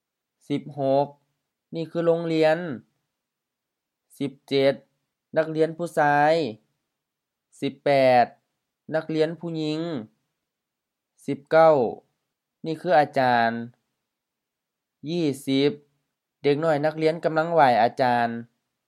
IsaanPronunciationTonesThaiEnglish/Notes
โลงเลียน lo:ŋ-li:an HR-HR โรงเรียน school